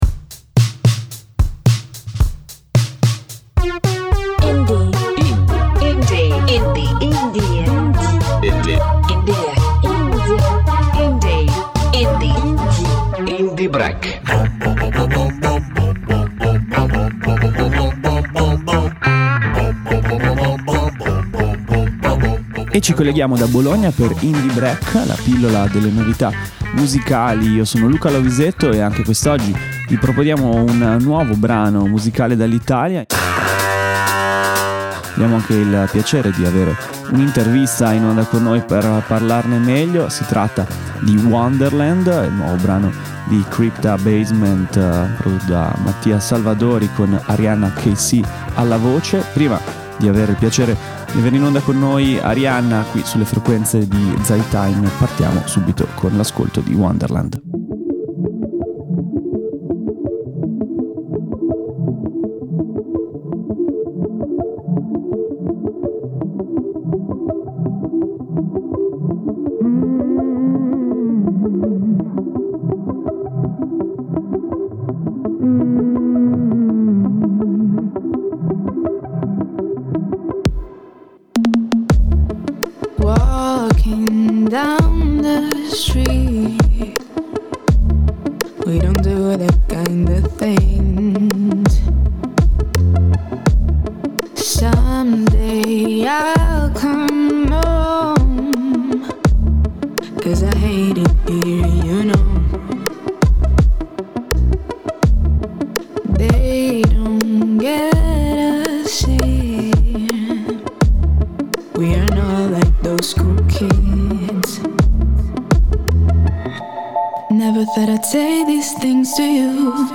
Intervista con la giovanissima cantautrice e vocalist pisana, voce dell'ultimo singolo del collettivo elettronico